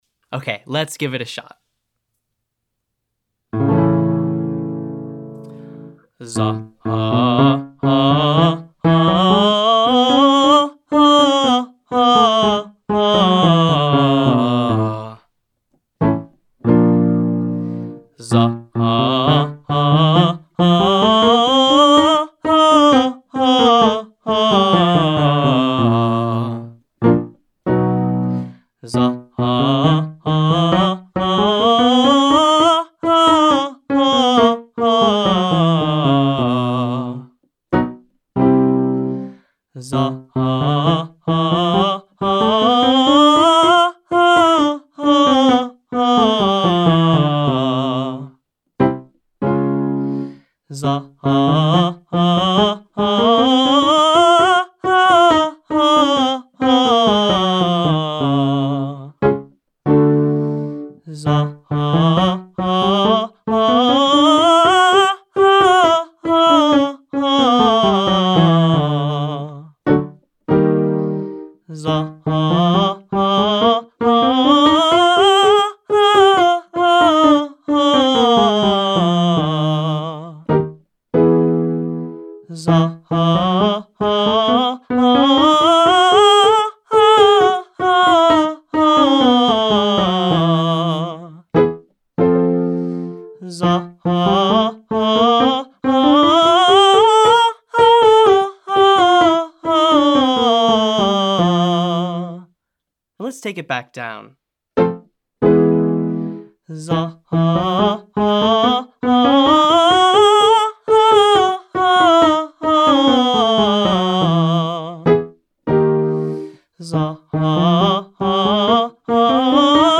For the first exercise we will cover an interval of an 11th up and down.
Notice how this covers a pretty wide area of our range from low chest voice eventually up into that light bouncy mix.